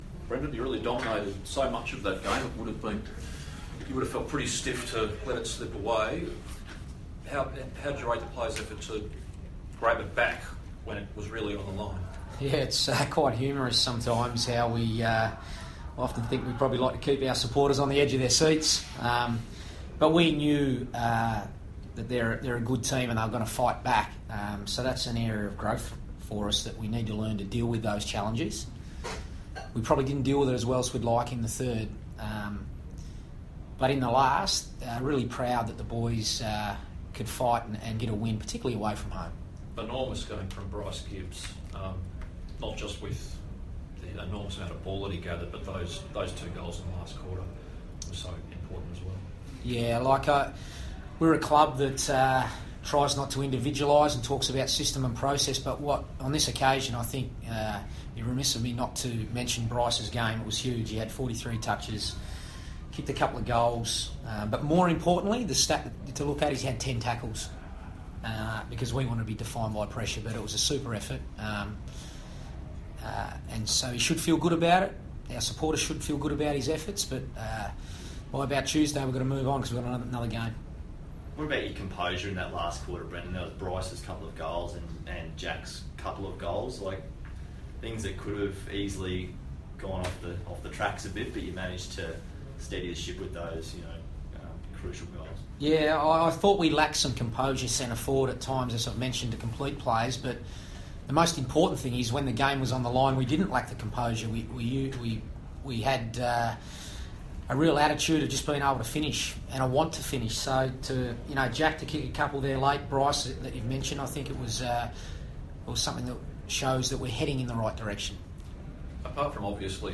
Carlton coach Brendon Bolton fronts the media after the Blues' 10-point win over the Suns.